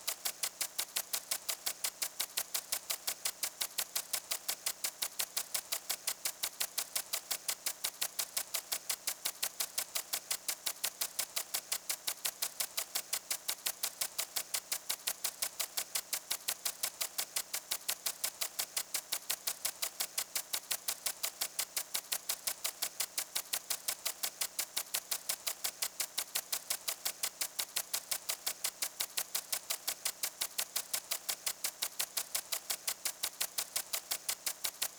freegardensfx-sprinkler_ofYWKXjn.wav